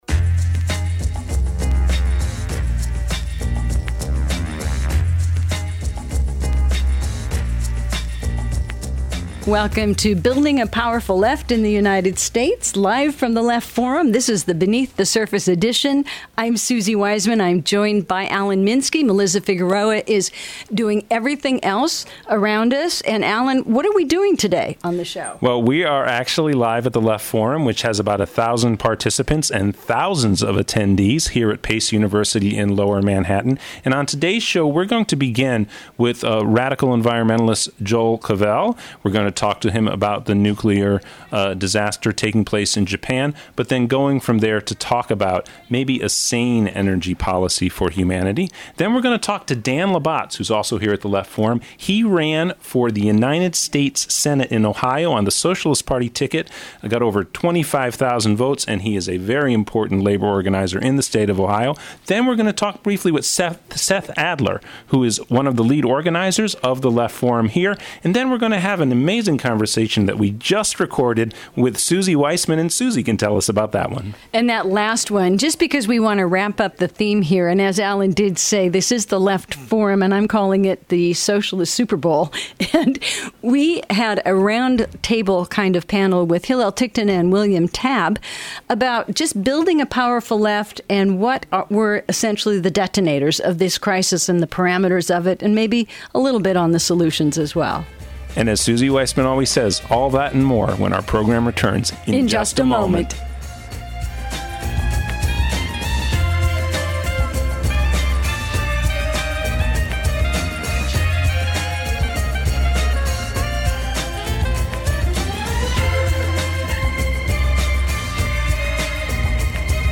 Live from the Left Forum – Show #1